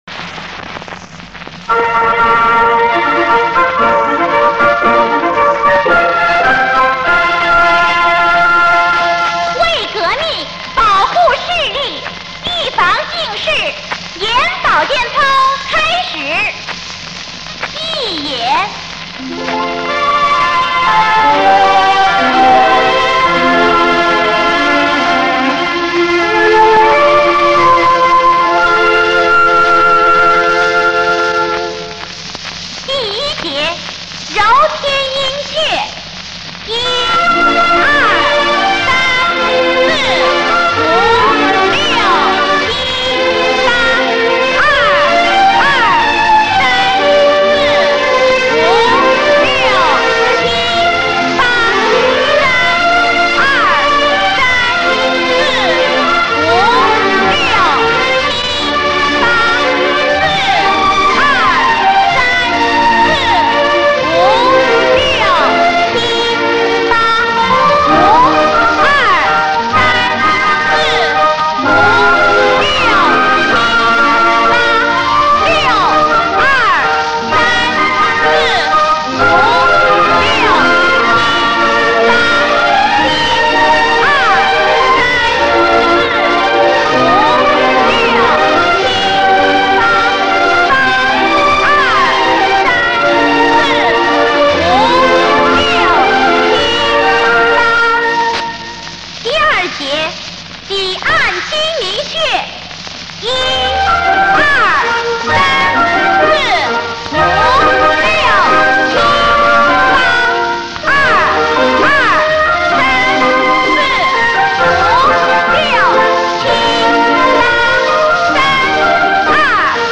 小时候常做的课间视力保健操，现在不知道学校里还是不是与以前一样在做，不过这样的音乐应该可以让一代人永远铭记。